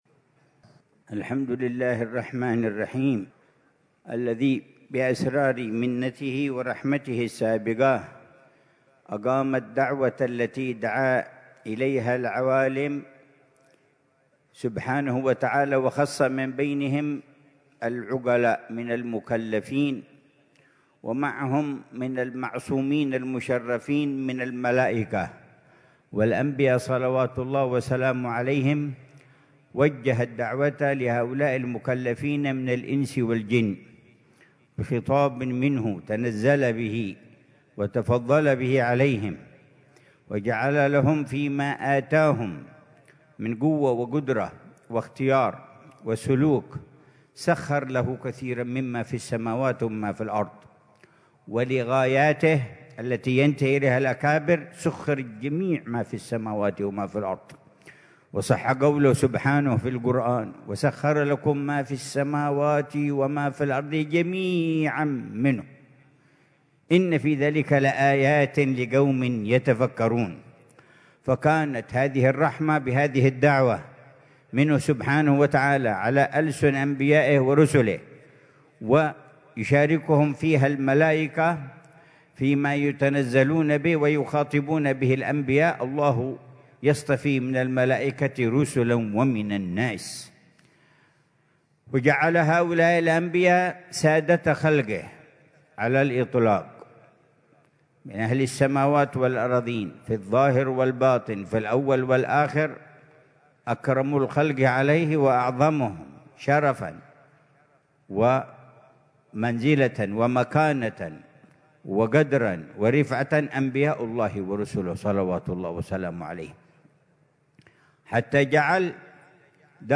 محاضرة العلامة الحبيب عمر بن محمد بن حفيظ ضمن سلسلة إرشادات السلوك ليلة الجمعة 16 جمادى الأولى 1447هـ في دار المصطفى، بعنوان: